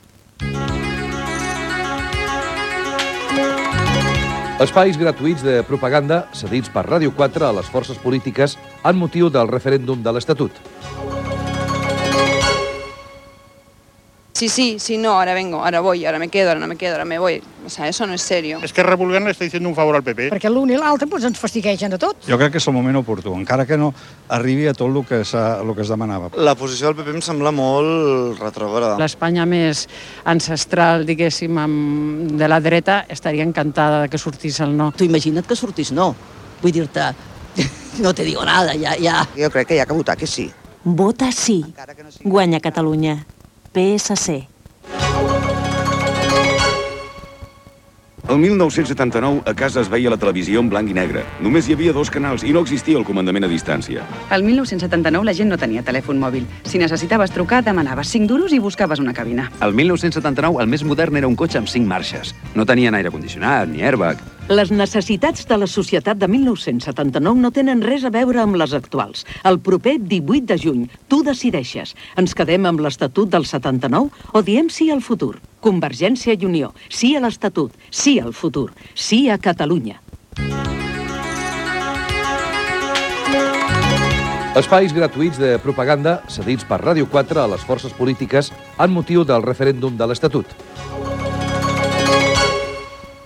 Careta del programa, propaganda del Partit Socialista de Catalunya i de Convergència i Unió en la campanya del Refèndum de l'Estatut d'Autonomia de Catalunya